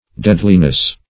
Deadliness \Dead"li*ness\, n.